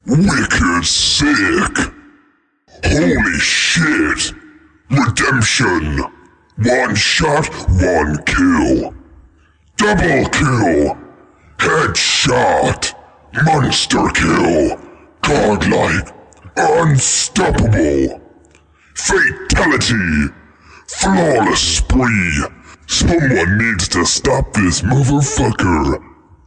游戏播报员
Tag: 麦克风 激动 声音 混响 英语 铁三角 19室 立体声 FlStudio